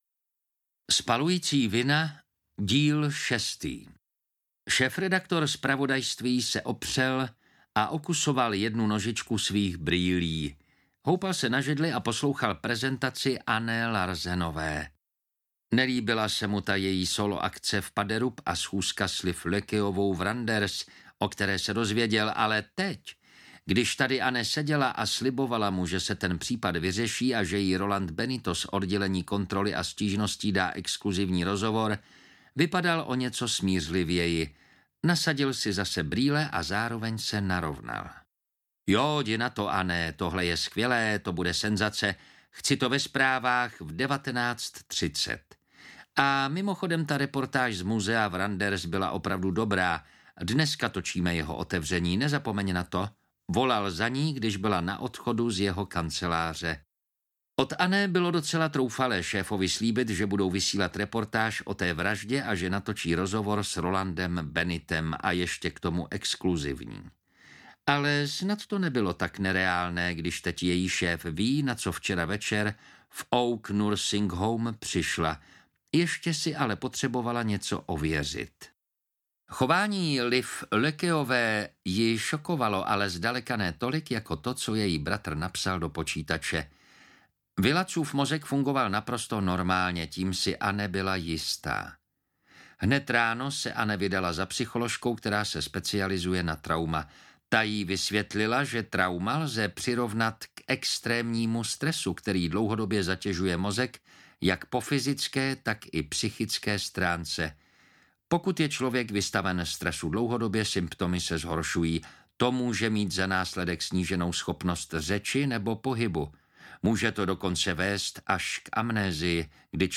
Spalující vina - Díl 6 audiokniha
Ukázka z knihy